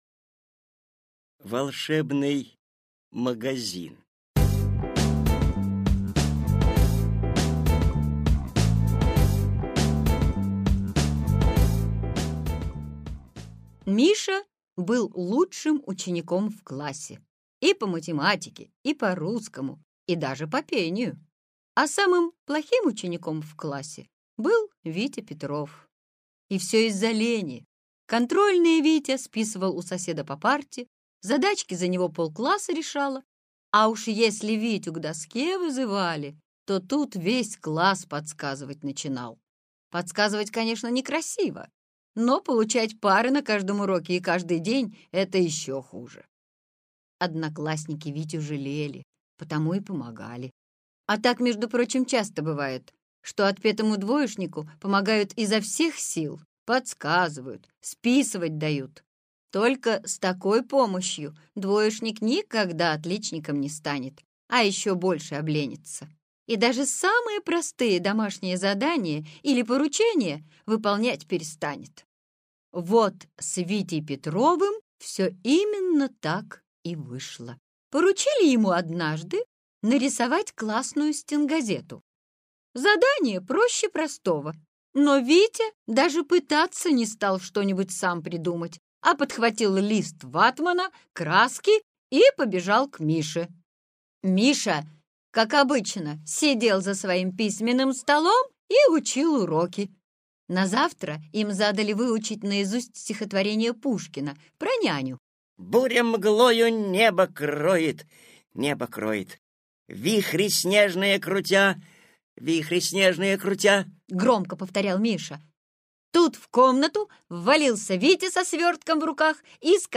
Аудиосказка "Волшебный магазин" про ленивого ученика Вову, который не хотел учиться и получить всё с помощью волшебства.